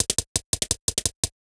UHH_ElectroHatD_170-02.wav